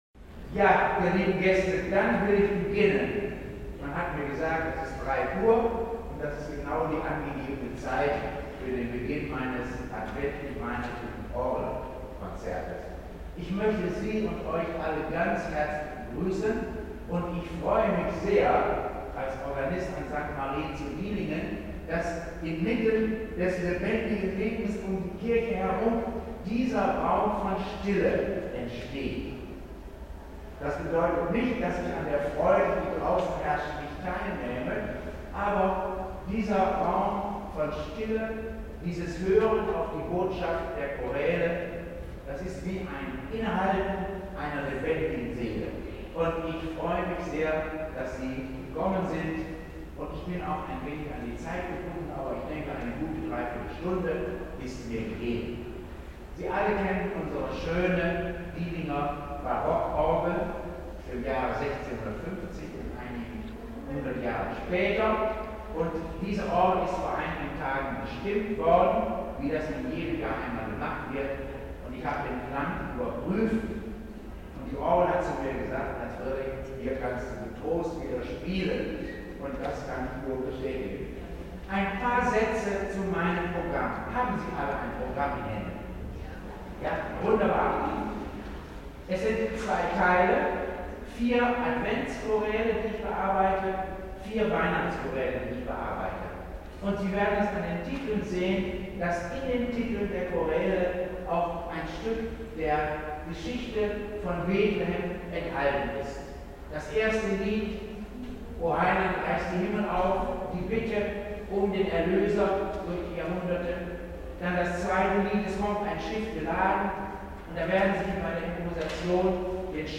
Adventskonzert - Advents- und Weihnachtslieder - Improvisationen an der Orgel
01 - Begrüßung